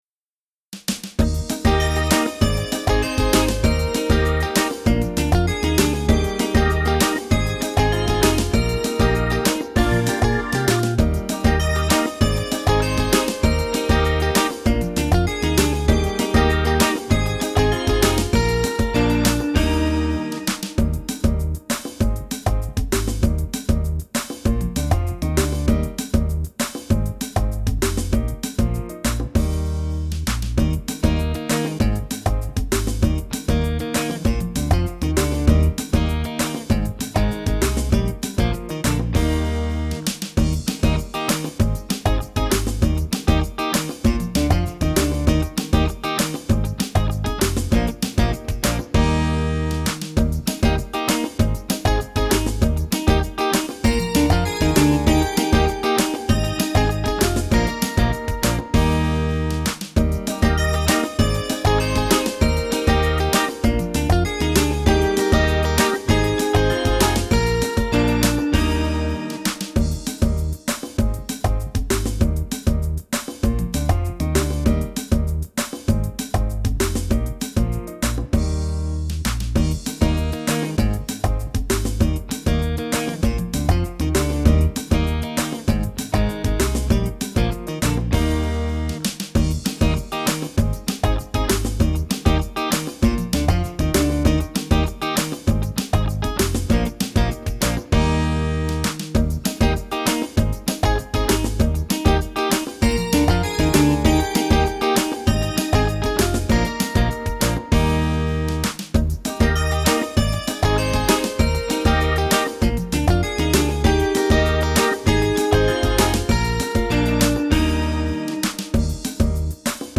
Key: E flat